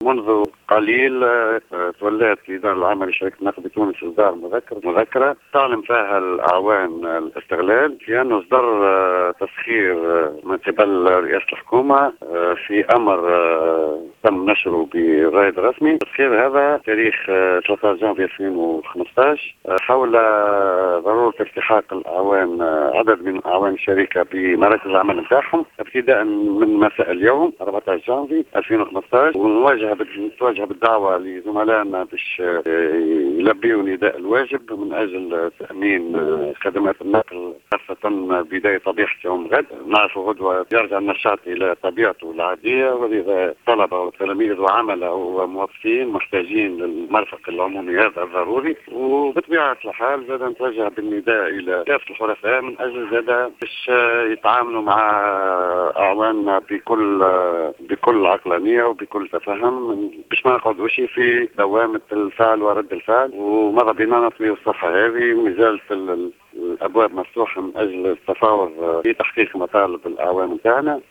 تصريح لجوهرة "اف ام"